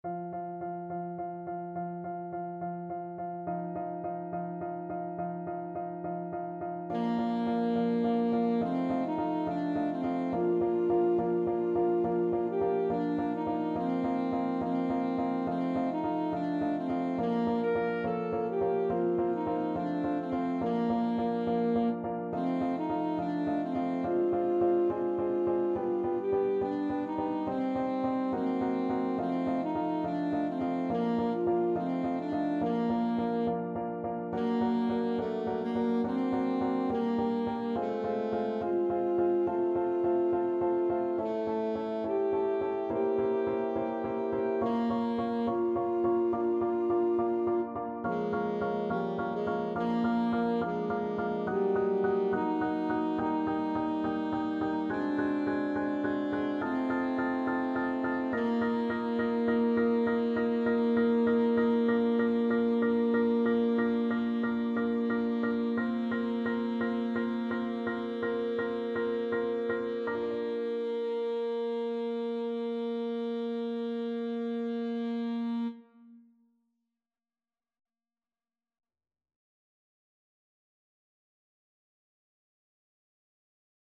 Classical Saint-Saëns, Camille Tortues (Tortoises aka Can-Can) from Carnival of the Animals Alto Saxophone version
Alto Saxophone
Andante maestoso
Bb major (Sounding Pitch) G major (Alto Saxophone in Eb) (View more Bb major Music for Saxophone )
4/4 (View more 4/4 Music)
Classical (View more Classical Saxophone Music)
saint_saens_the_tortoise_ASAX.mp3